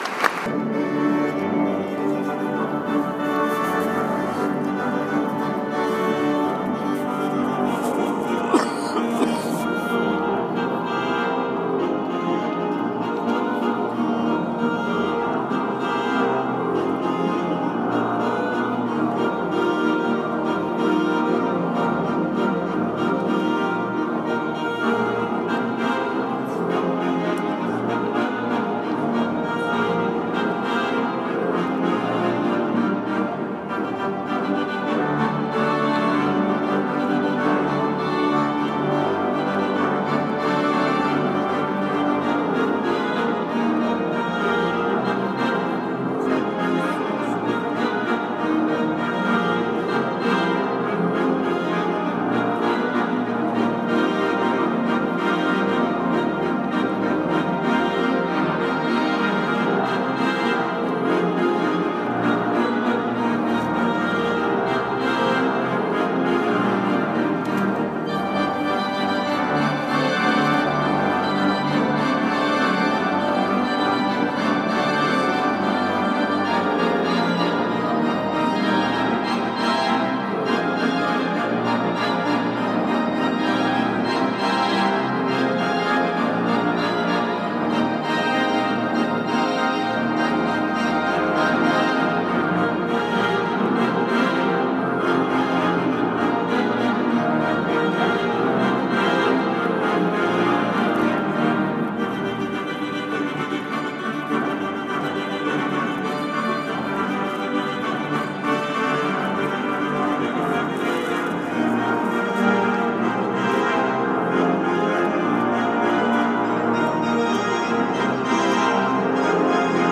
Brel à la Cathédrale de Bourges
Double-Coeur a réussi à nous apporter un grand moment de bonheur avec ce concert : Chansons de Jacques Brel à l’orgue.
L’instrument orgue est utilisé dans son ensemble.
A noter que le Grand Orgue de Bourges  a 350 ans et bénéficie d’une longue histoire de restauration et d’amélioration tout en restant un orgue classique et l’entendre jouer  du contemporain est étonnant.